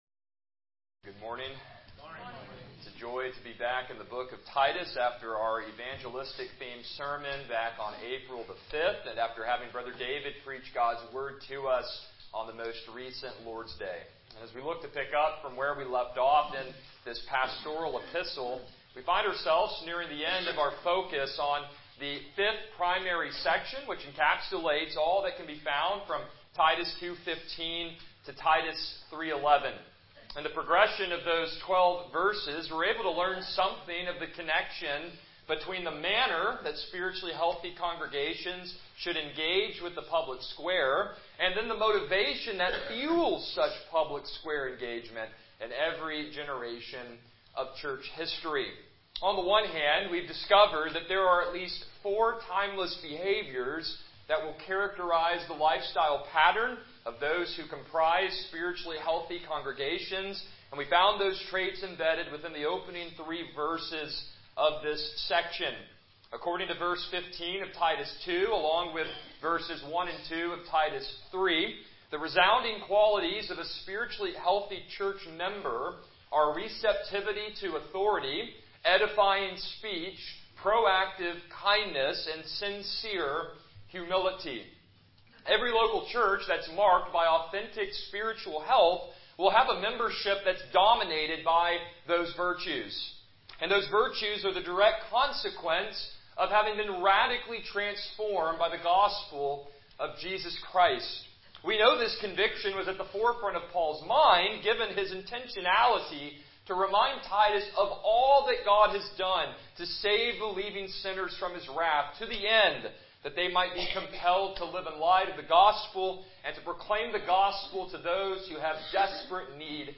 Service Type: Morning Worship